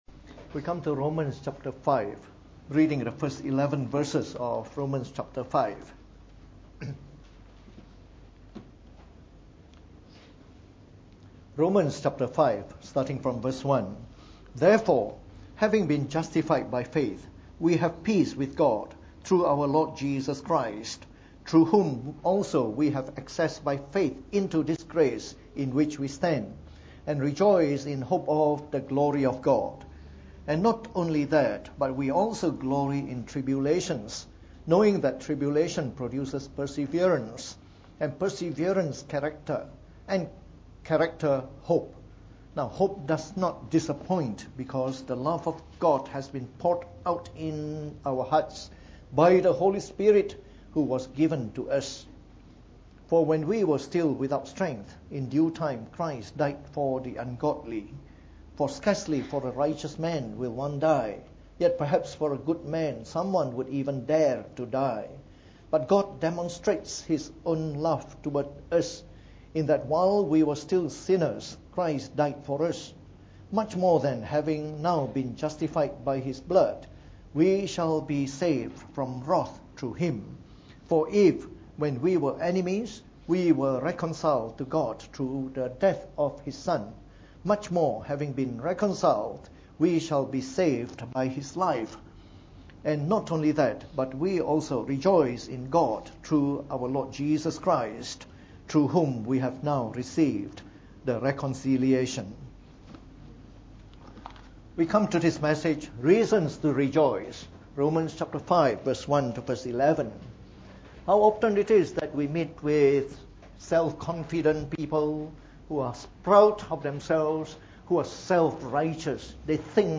From our series on the Book of Romans delivered in the Morning Service.